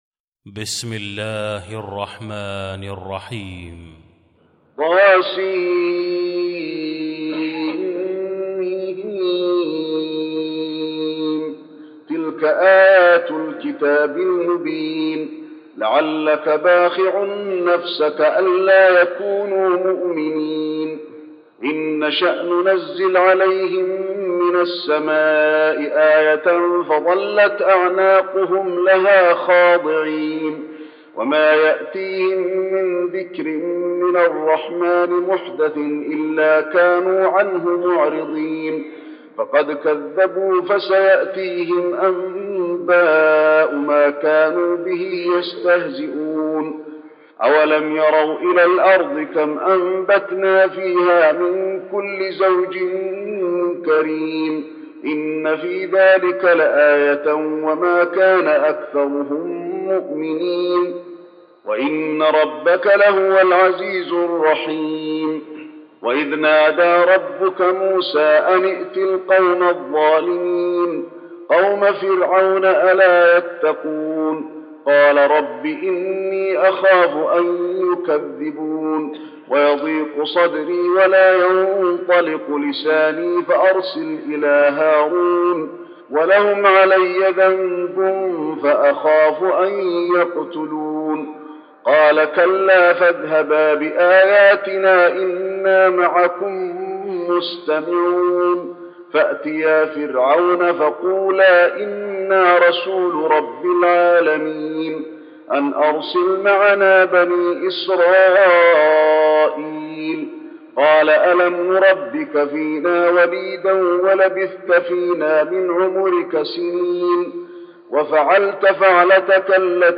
المكان: المسجد النبوي الشعراء